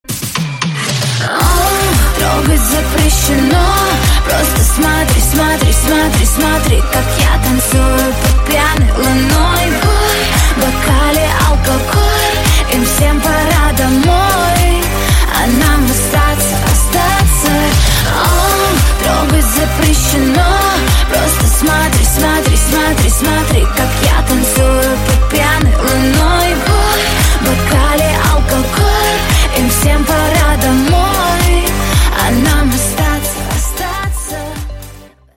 • Качество: 128, Stereo
чувственные